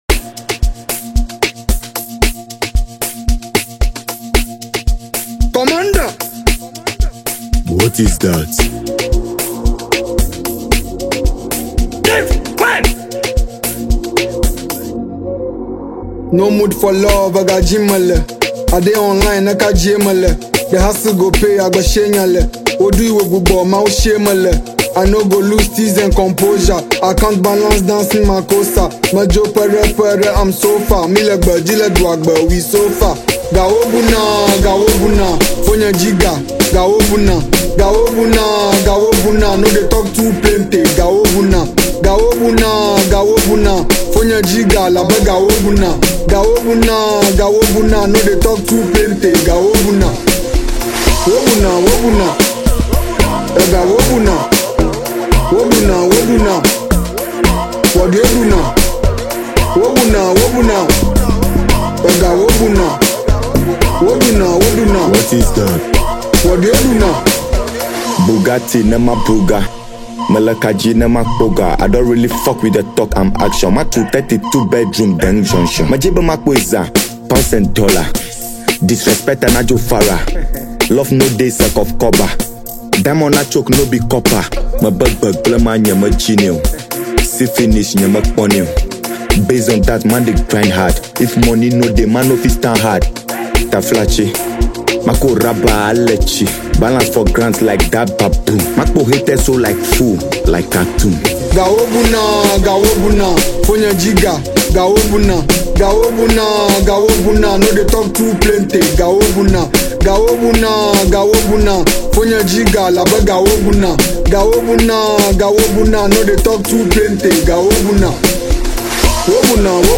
Afro-House and Amapiano influences
smooth vocals and lyrical charm